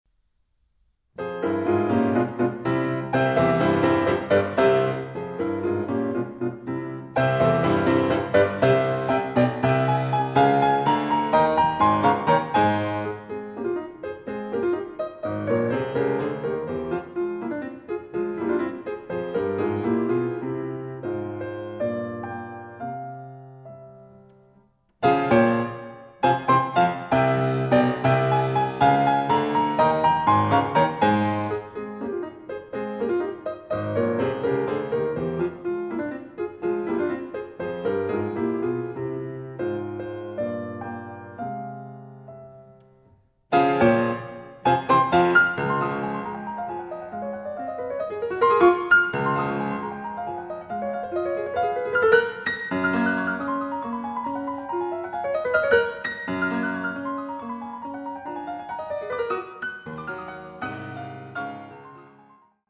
Pianoforte